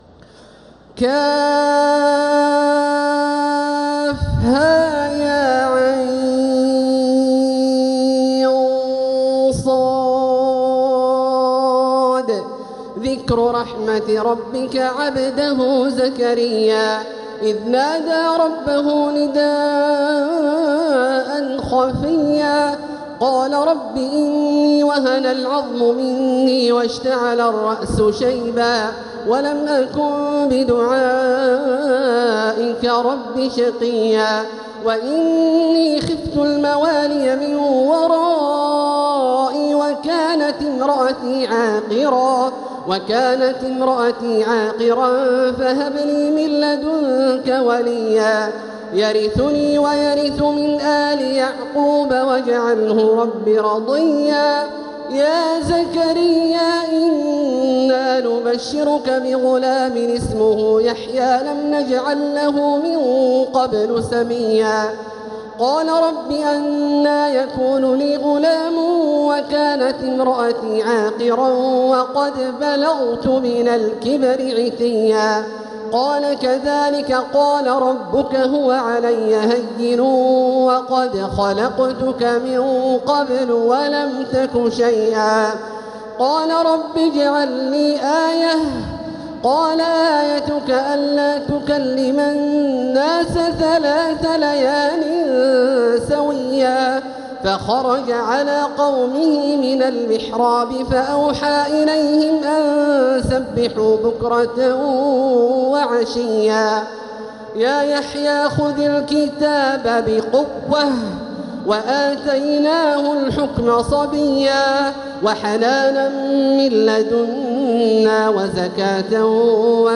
سورة مريم Surat Maryam > مصحف تراويح الحرم المكي عام 1446هـ > المصحف - تلاوات الحرمين